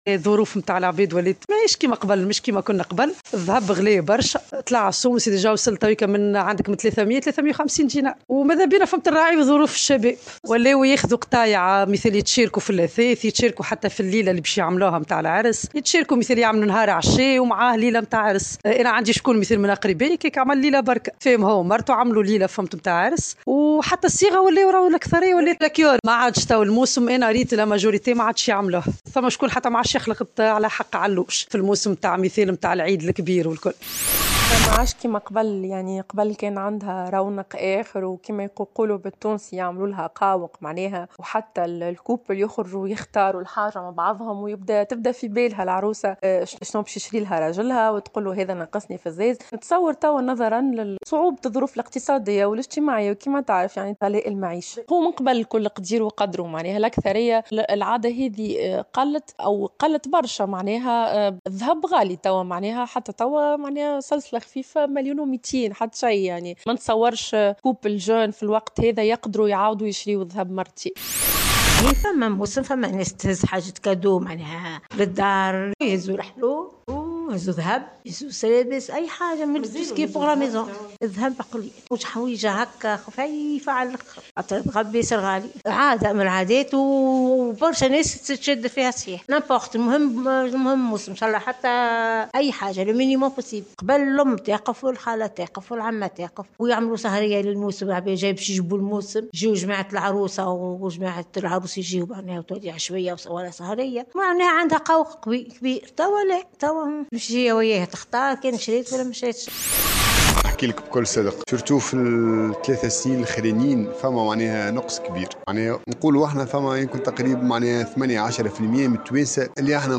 وفي هذا الإطار أكد عدد من المواطنين في تصريحات لـ"الجوهرة أف أم"، أن هناك من يزال متمسكا بهذه العادة التي لا يمكن الاستغناء عنها، وفق قولهم.